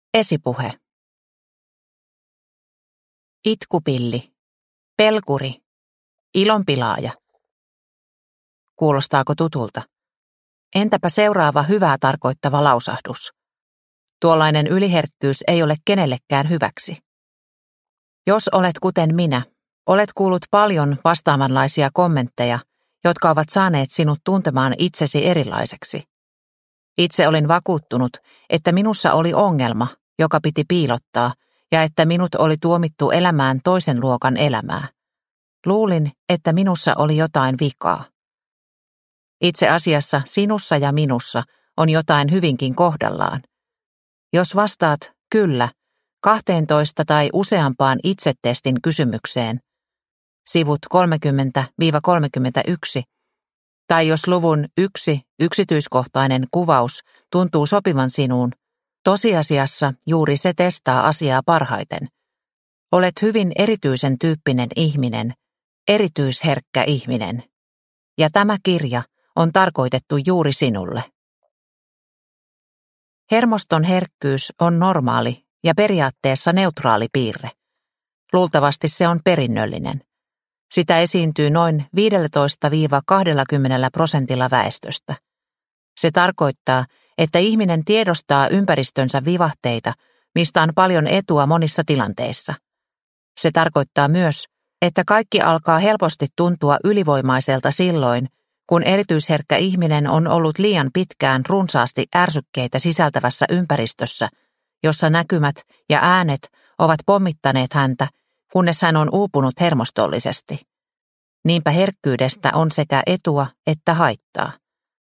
Erityisherkkä ihminen – Ljudbok – Laddas ner